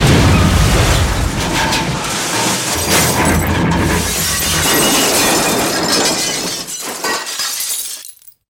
Звуки поезда